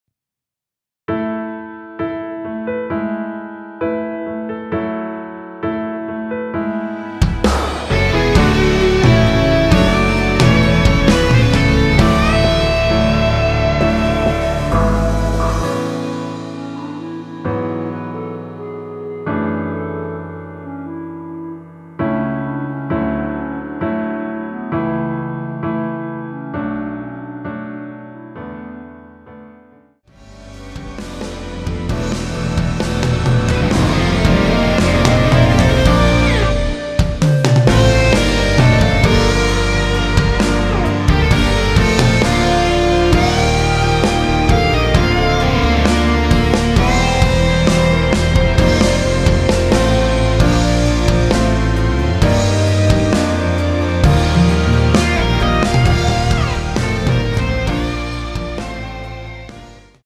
원키에서(-4)내린 멜로디 포함된 MR입니다.(미리듣기 확인)
앞부분30초, 뒷부분30초씩 편집해서 올려 드리고 있습니다.
중간에 음이 끈어지고 다시 나오는 이유는